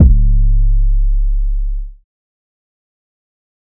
Southside 808 (3).wav